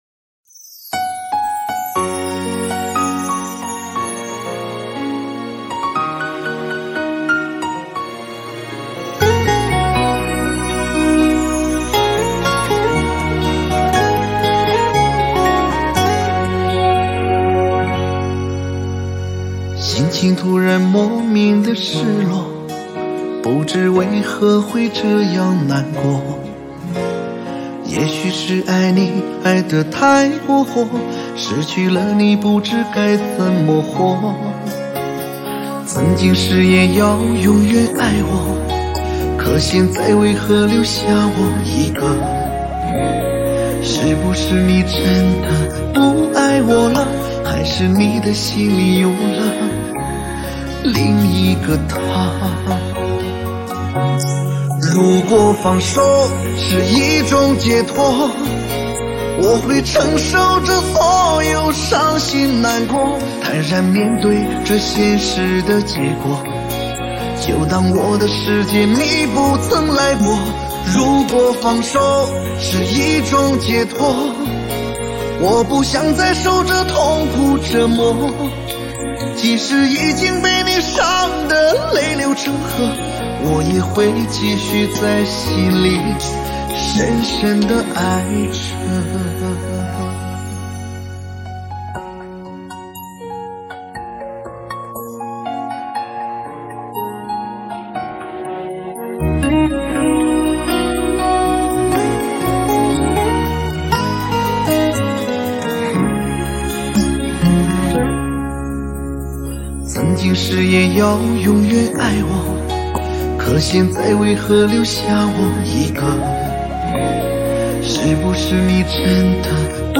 4/4 60以下
华语